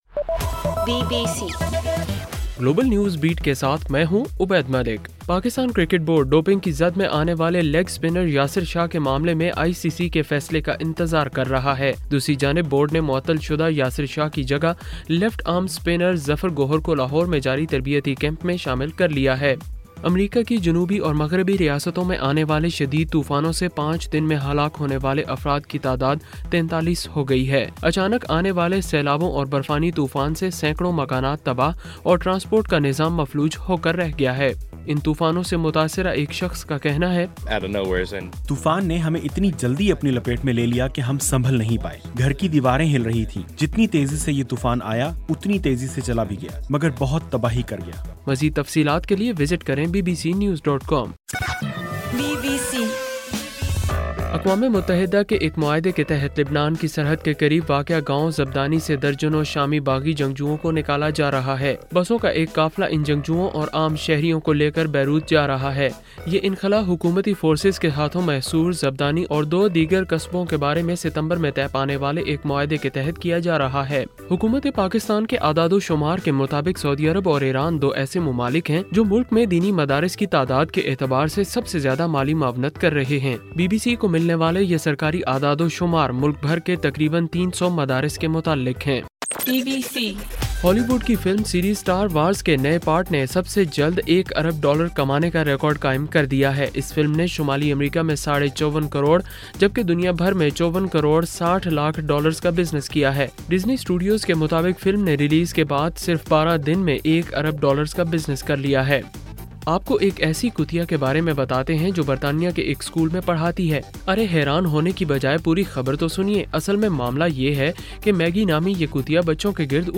دسمبر 29: صبح 1 بجے کا گلوبل نیوز بیٹ بُلیٹن